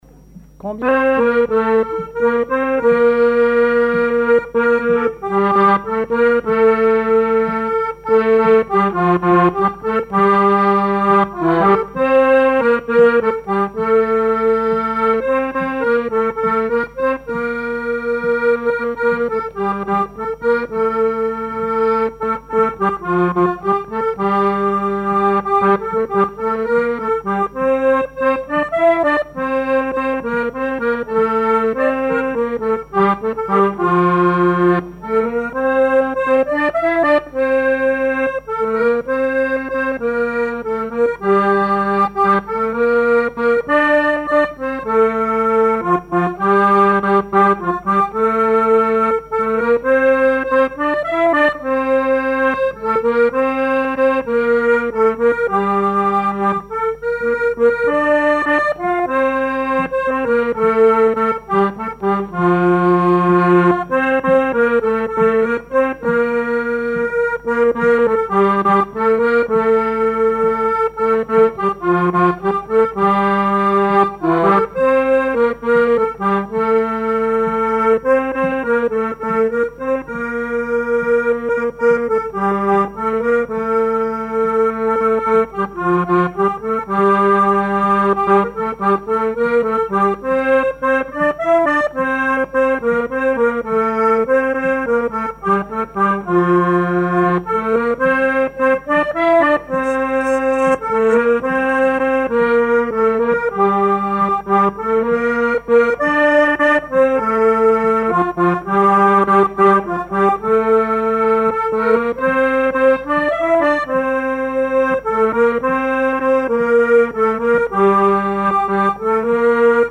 danse : valse
Genre strophique
collectif de musiciens pour une animation à Sigournais
Pièce musicale inédite